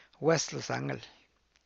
West Los Angl[We’st Lohs A’nngl]